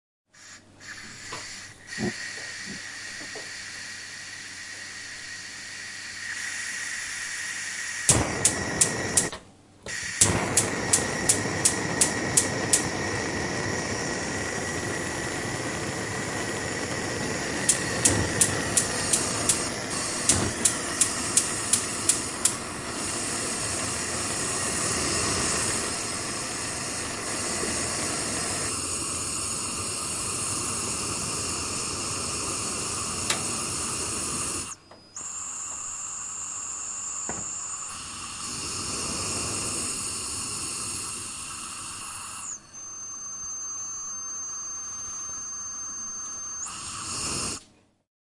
老式录音机
描述：zoom h4n
Tag: 磁带 卡带